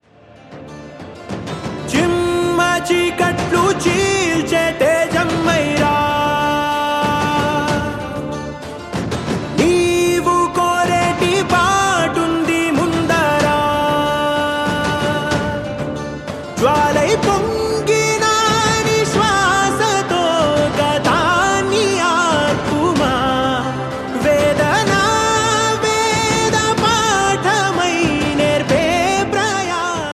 love song ringtone
romantic ringtone download